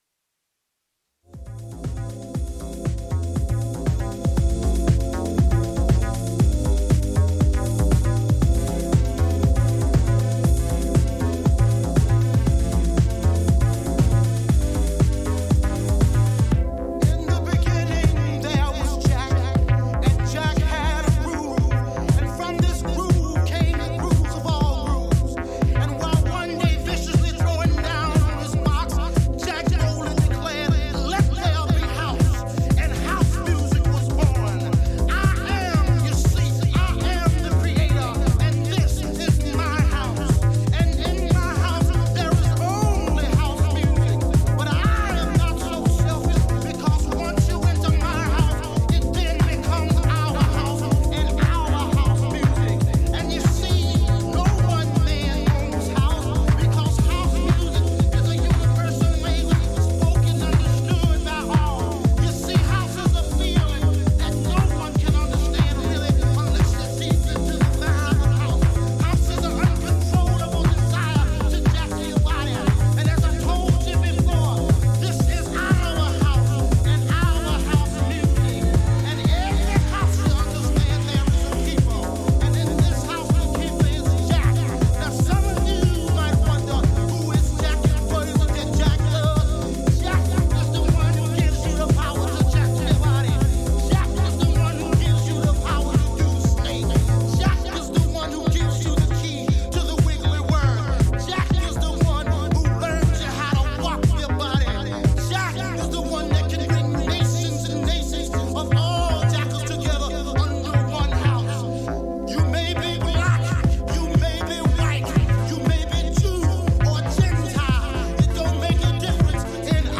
ジャンル(スタイル) CLASSIC HOUSE / DEEP HOUSE / CHICAGO HOUSE